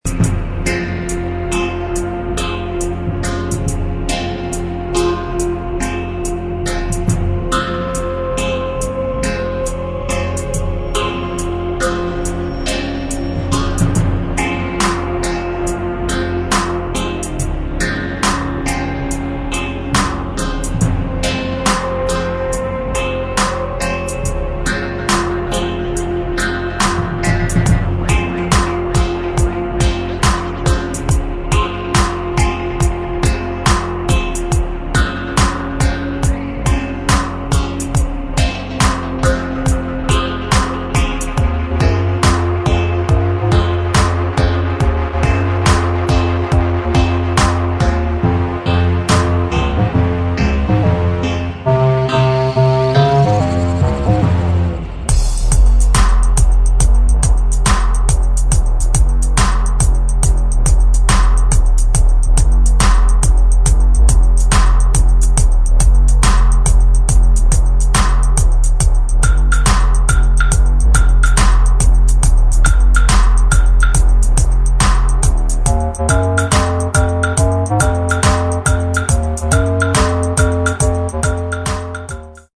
[ DUBSTEP / DUB / REGGAE ]